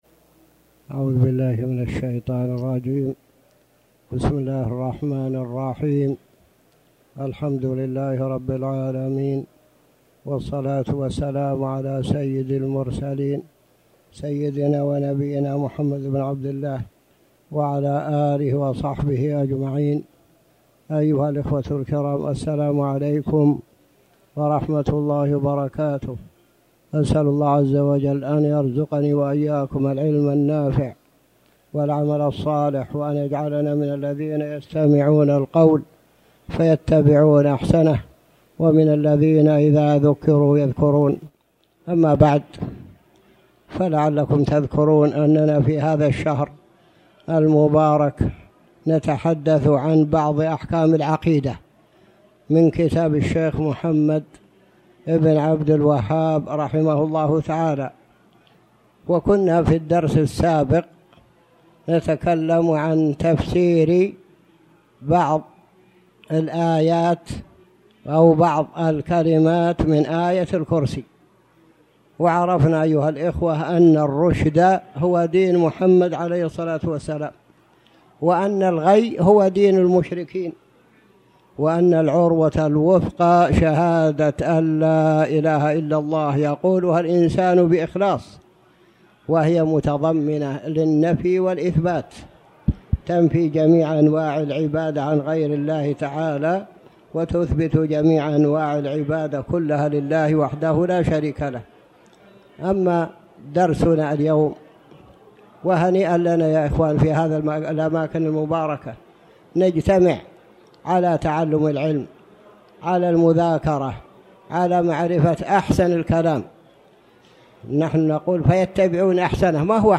تاريخ النشر ٢٤ جمادى الآخرة ١٤٣٩ هـ المكان: المسجد الحرام الشيخ